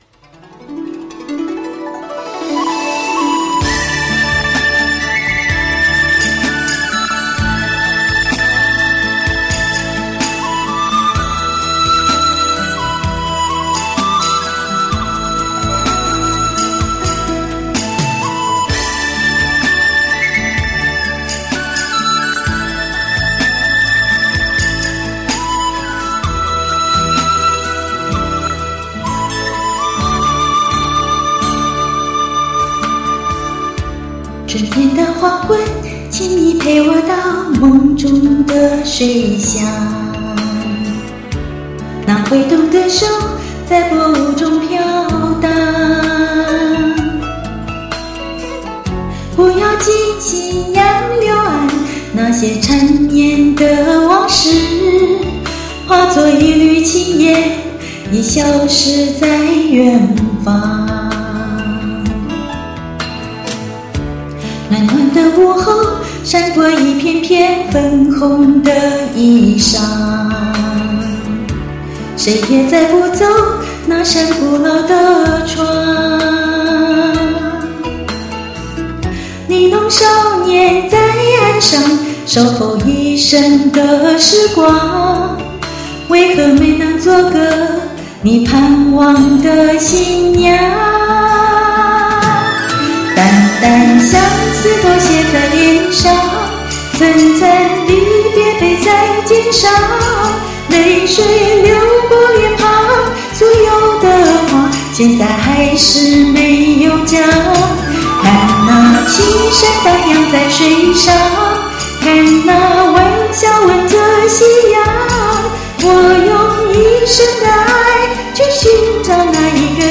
其实是因为伴奏无法免费下载，然而可以试听，因而用克难的方式，一面播放试听伴奏，再同步录下来。 所以伴奏的音效较差。
好像是干声和伴奏一起外录的？